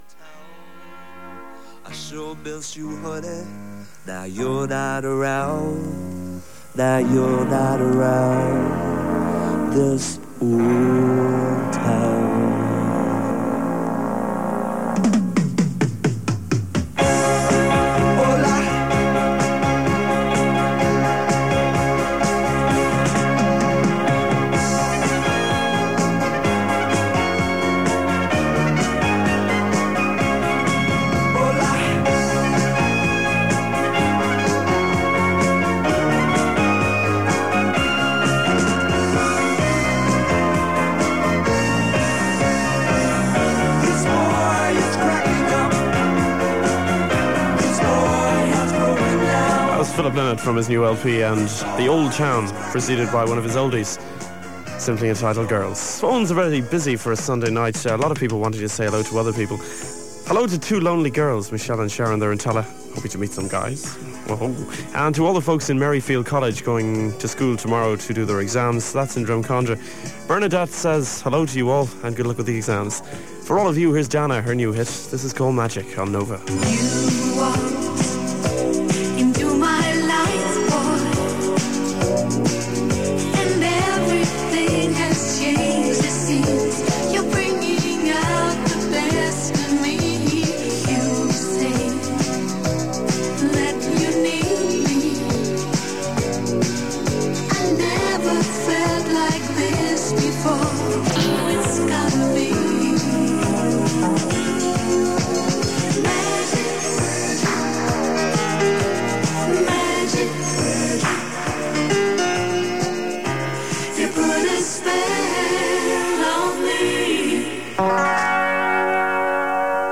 Music is a mixture of chart, oldies and ballads and adverts from Limerick City and surrounding towns are heard. There are some technical issues during the programme and crackle is audible on the FM reception.